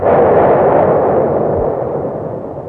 Rocket2.wav